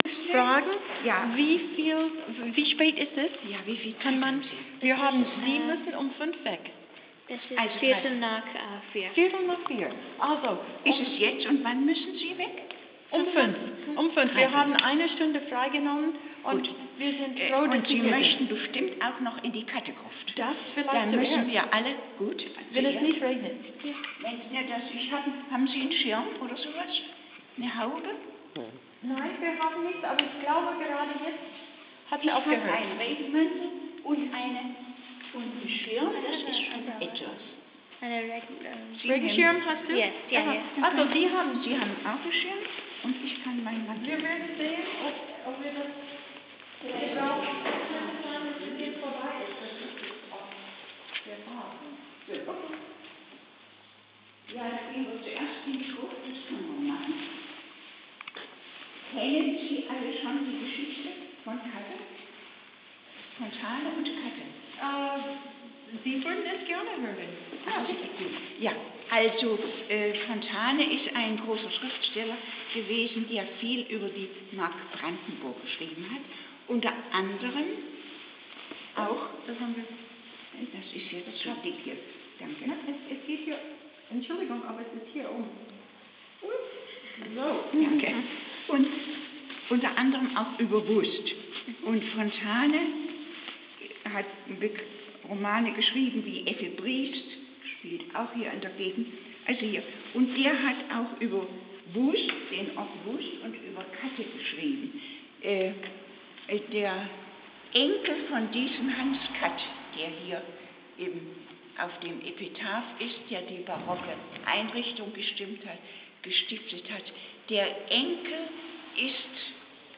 Bei der Kirche in Wust / At the Church in Wust
Die Führung fängt an / The tour begins